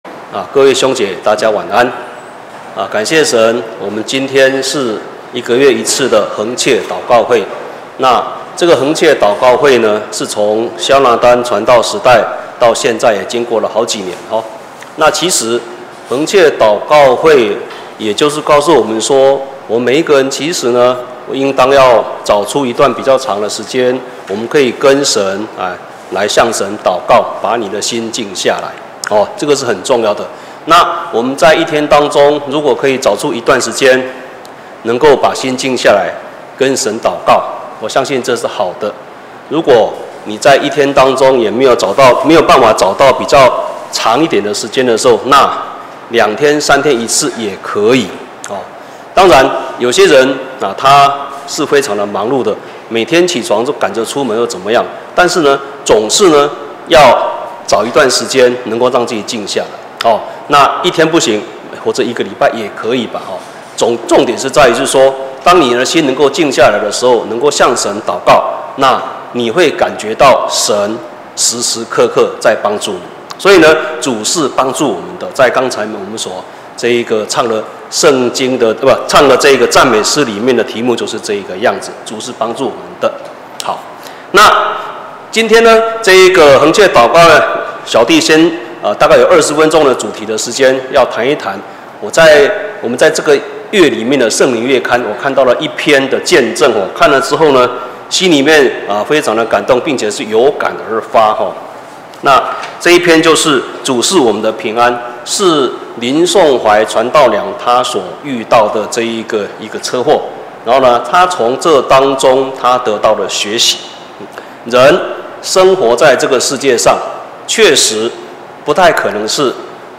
2020年10月份講道錄音已全部上線